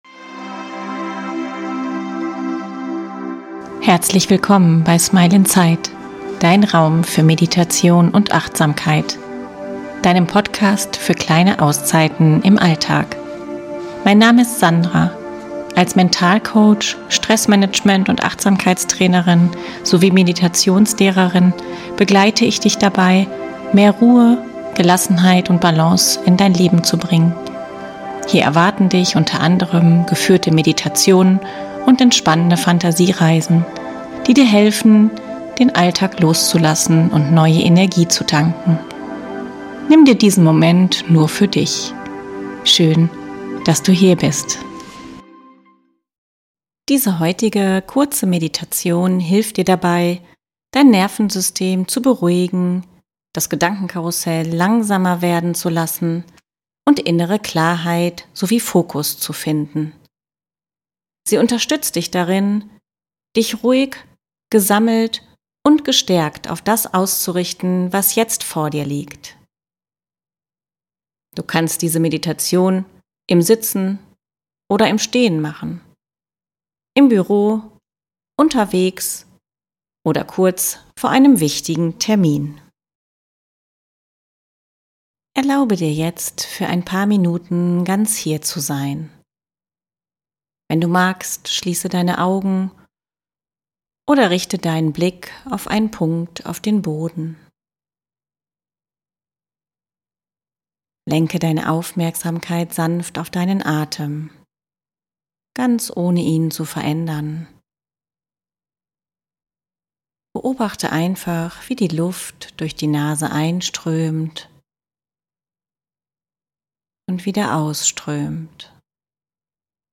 Mit sanfter Atemführung, innerer Sammlung und klaren